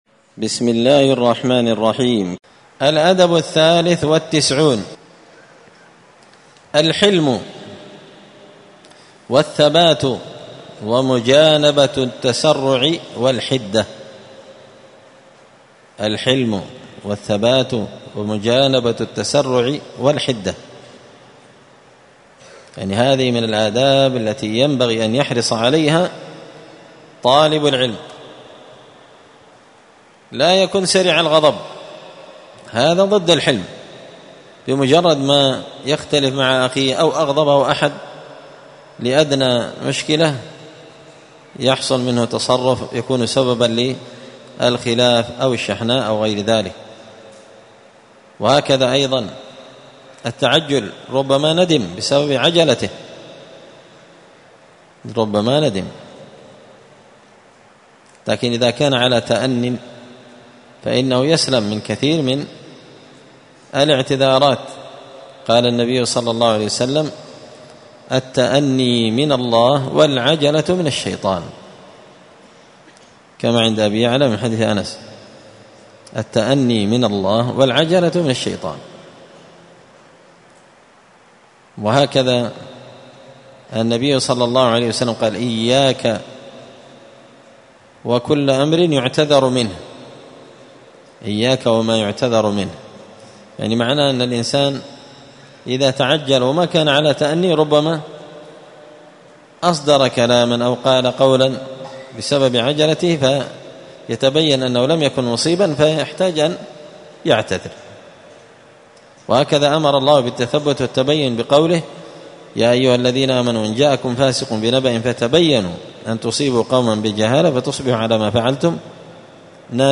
*الدرس الرابع بعد المائة (104) الأدب الثالث والتسعون الحلم والثبات ومجانبة التسرع والحدة*
مسجد الفرقان قشن_المهرة_اليمن